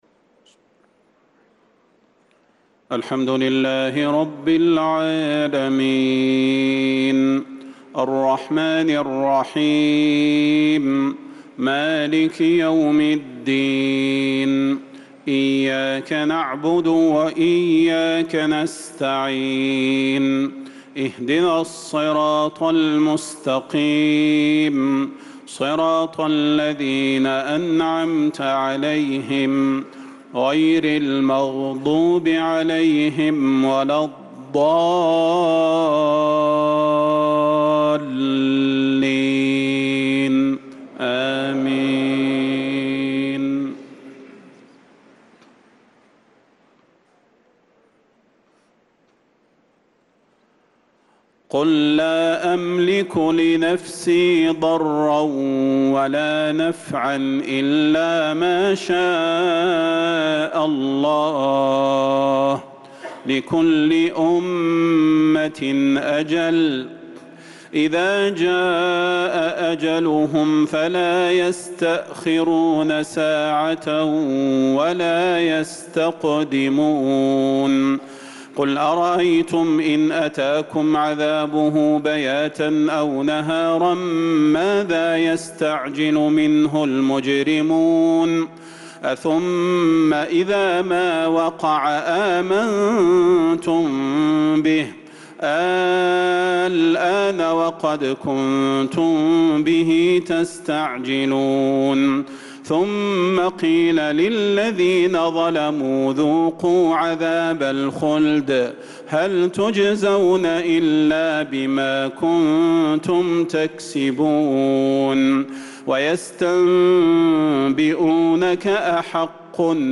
صلاة العشاء للقارئ صلاح البدير 1 محرم 1446 هـ
تِلَاوَات الْحَرَمَيْن .